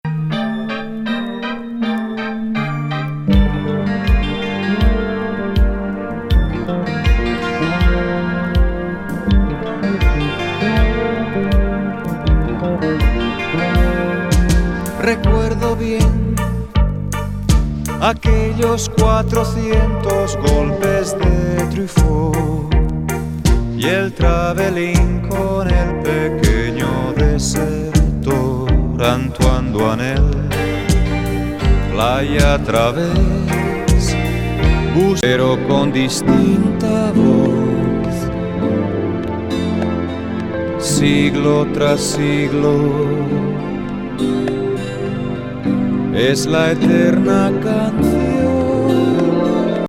スペインのSSW84年作。極上メロウAOR